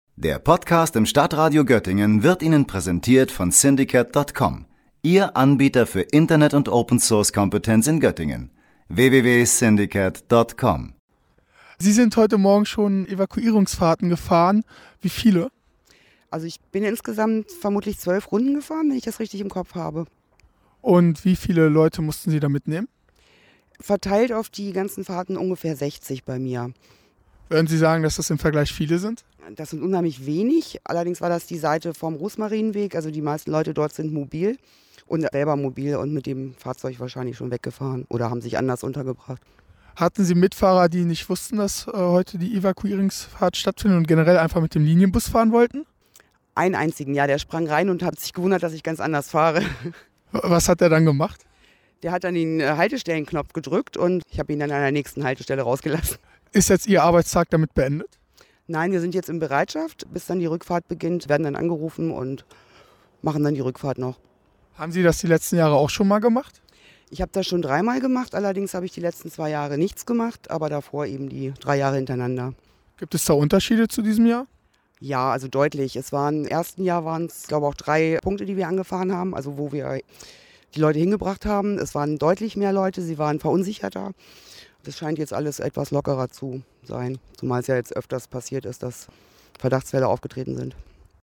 Beiträge > Blindgängerbeseitigung: Busfahrerin erzählt von den Evakuierungsfahrten - StadtRadio Göttingen